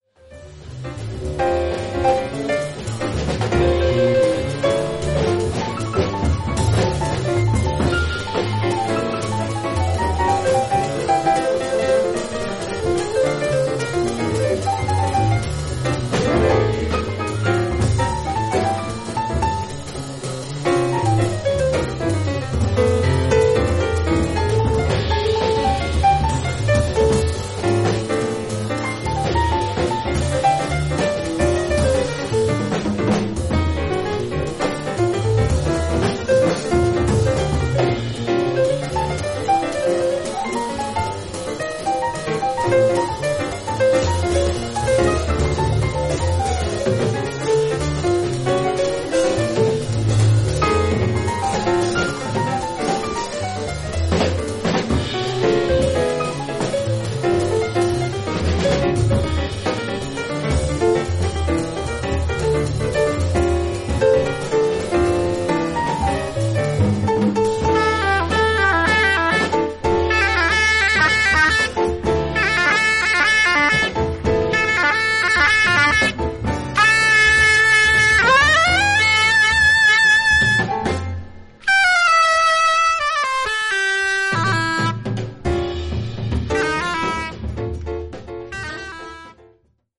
フロリダ出身のジャズ・サックス奏者